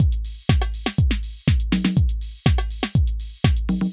AIR Beat - Mix 1.wav